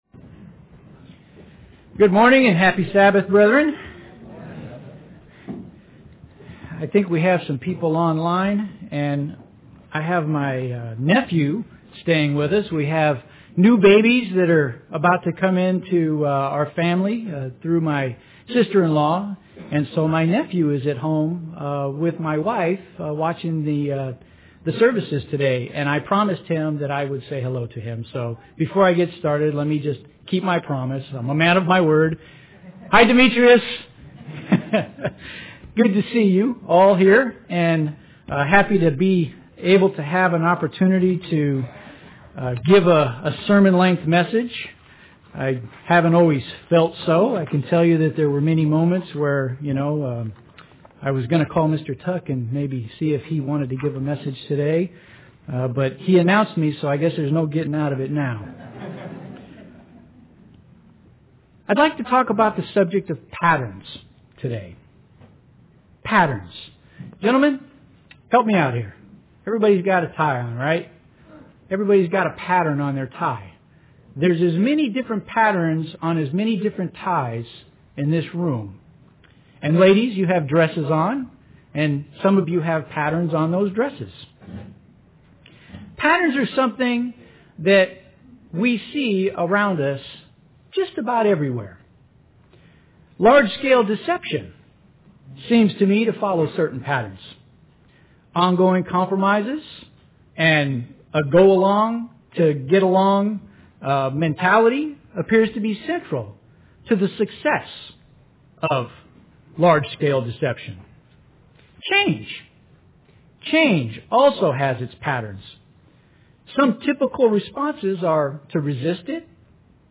UCG Sermon Notes “Patterns”...